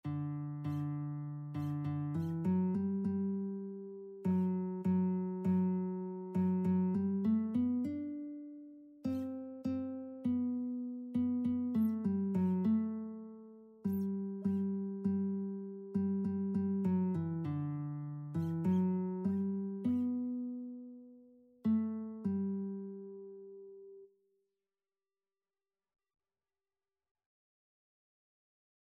Christian
4/4 (View more 4/4 Music)
Classical (View more Classical Lead Sheets Music)